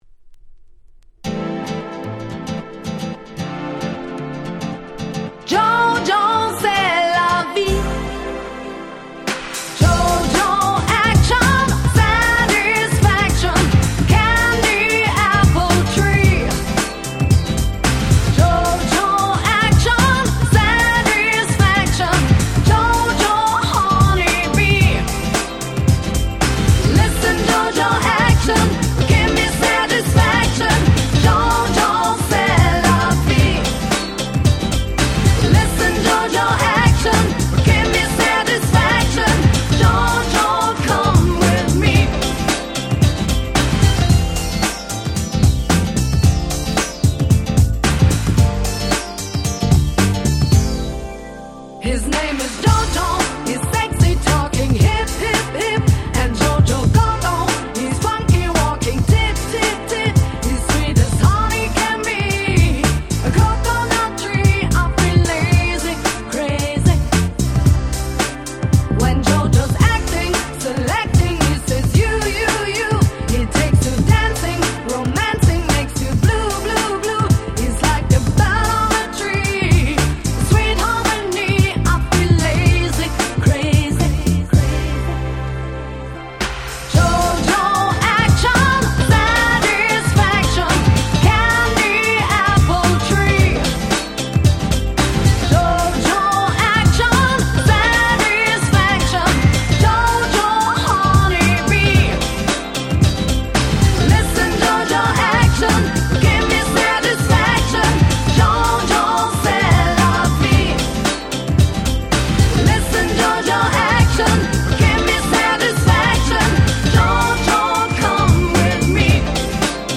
97' Smash Hit Dance Pop !!
いわゆる「ADM Beat」のキャッチーダンスポップ !!
Euro Dance